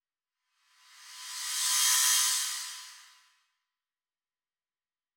Crashes & Cymbals
MB Reverse Crash (1).wav